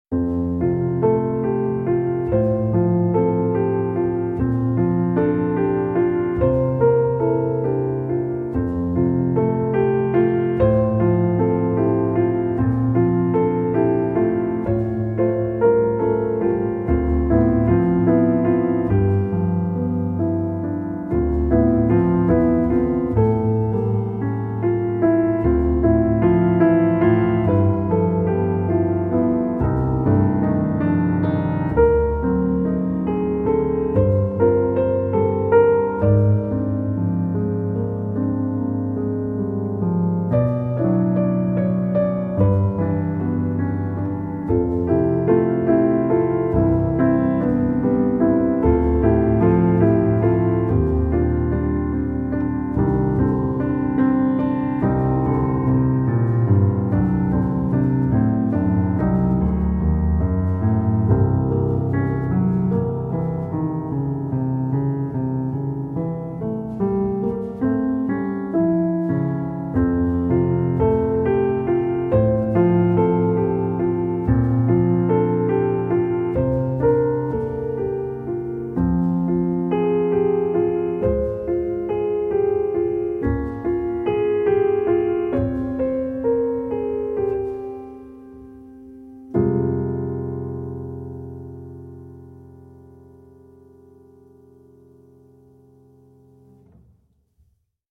سبک موسیقی (Genre) موسیقی متن